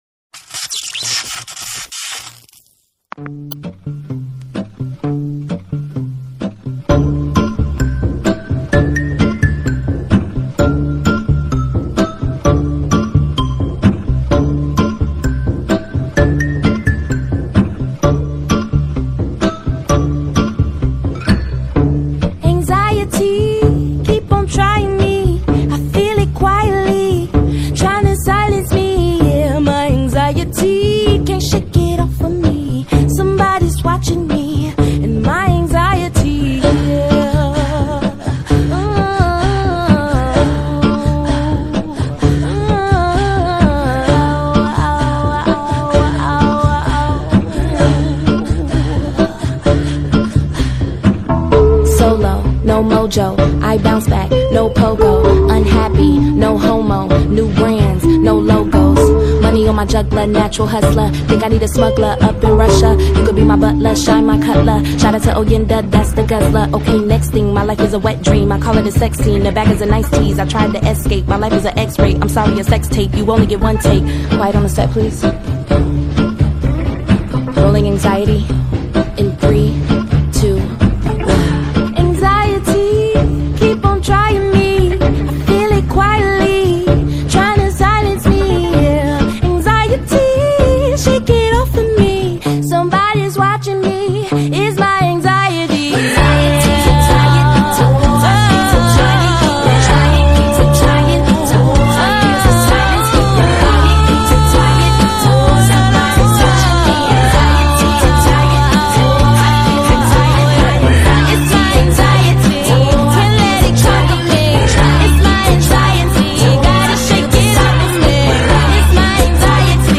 Zumba - Pop